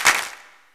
SWCLAP03.wav